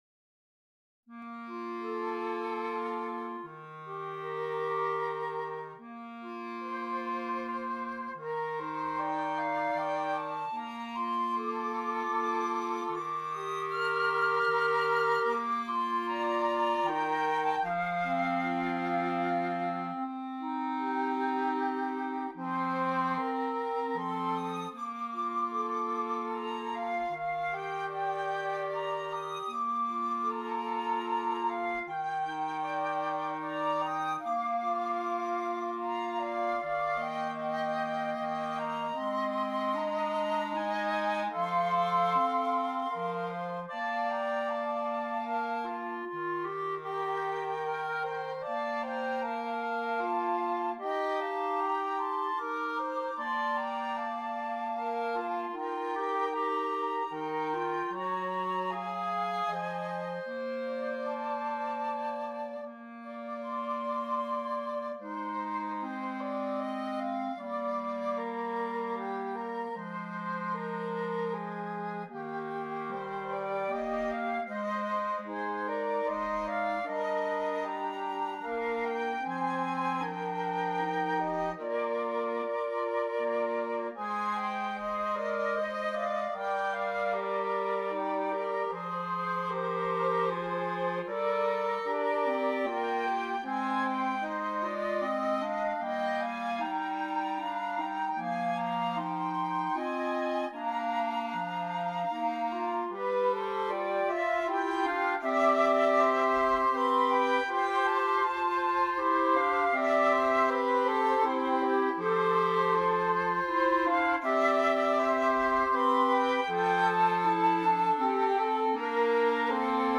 Gattung: Für 2 Flöten und 2 Klarinetten
Besetzung: Ensemblemusik für 4 Holzbläser